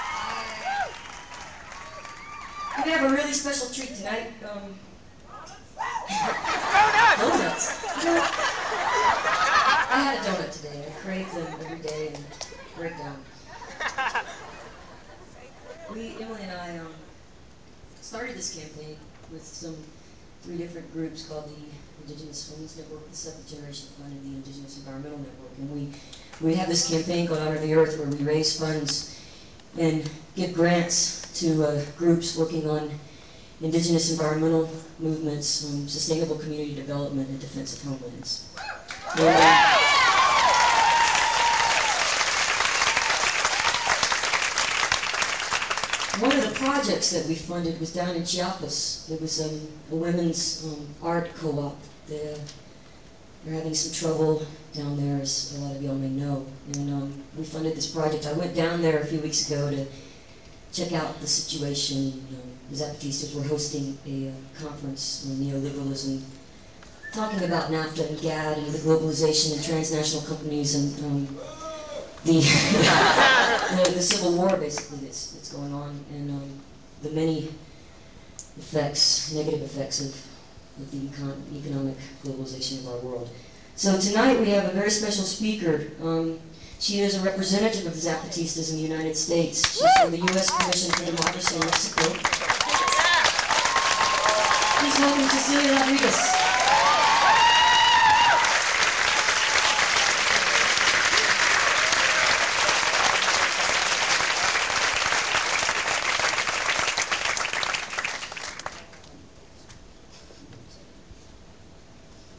lifeblood: bootlegs: 1996-08-24: las cruces, new mexico